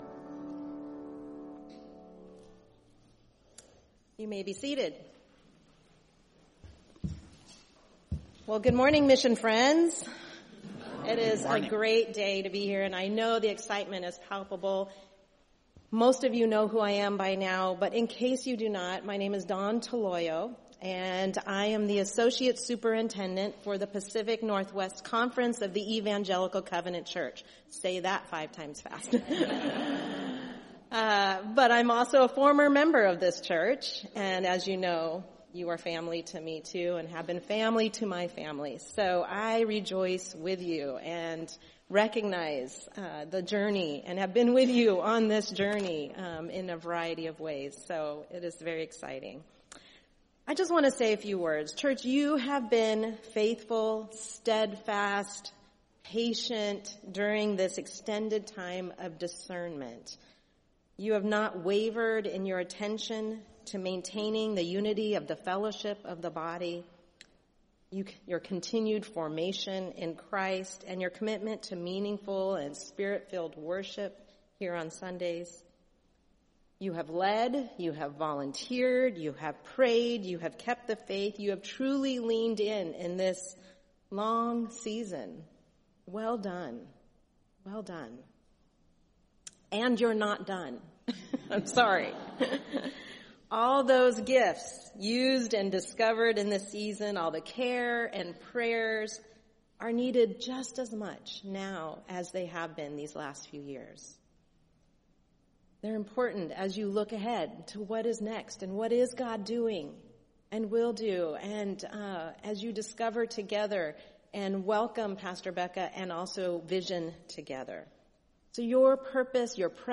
Installation Service